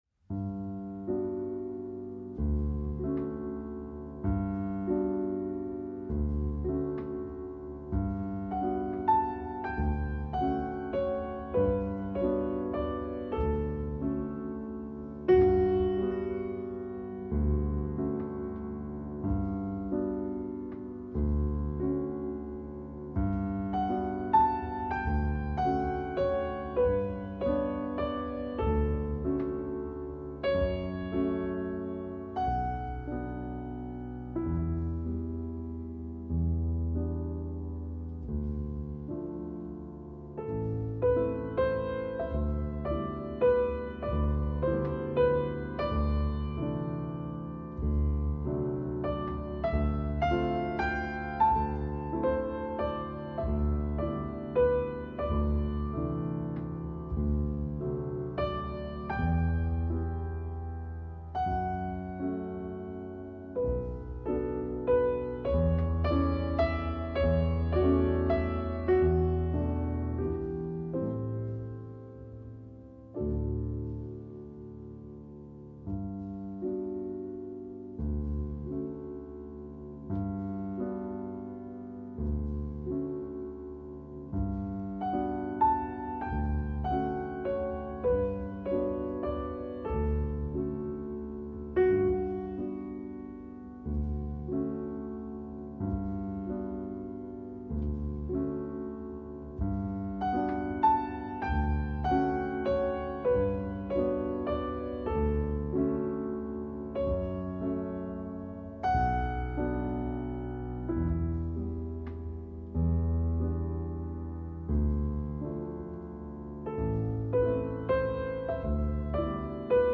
Жанр: Classical Издание